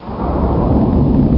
Crash Inst Sound Effect
crash-inst.mp3